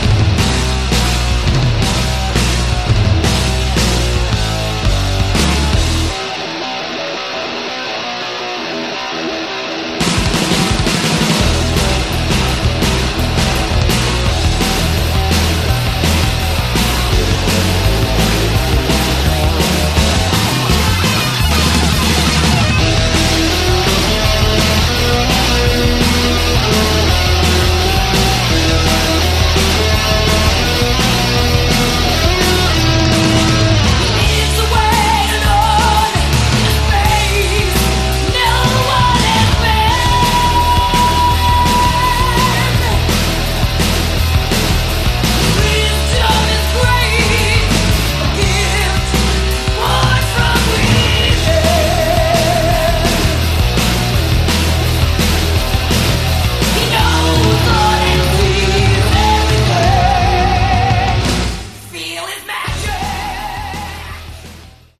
Category: Melodic Metal
guitar
vocals
drums
bass